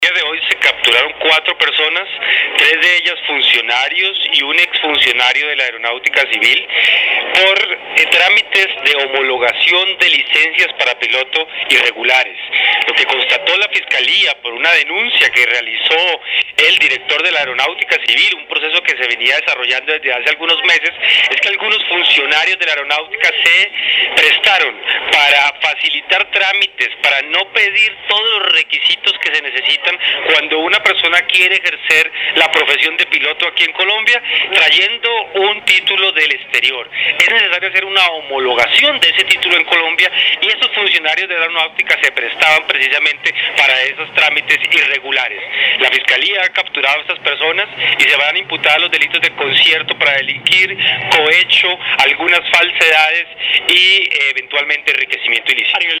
Declaraciones Vicefiscal General de la Nación, Jorge Fernando Perdomo Torres
Lugar: Universidad Externado de Colombia. Bogotá, D. C.